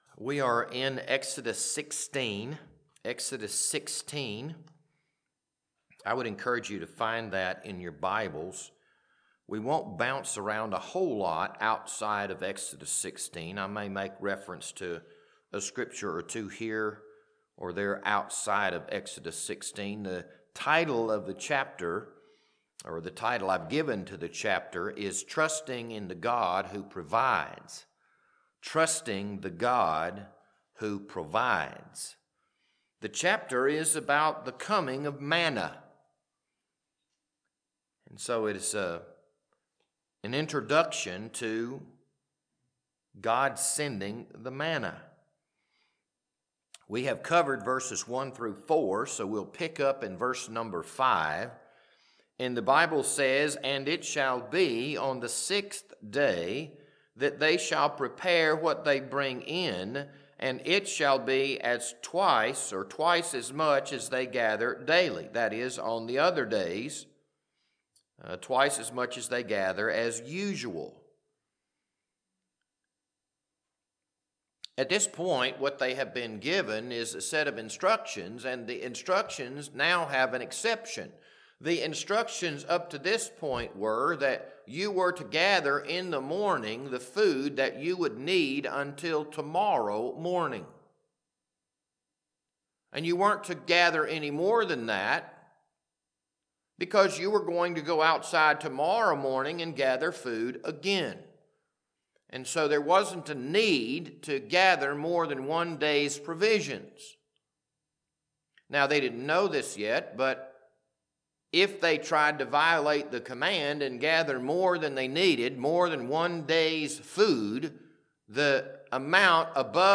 This Wednesday evening Bible study was recorded on February 18th, 2026.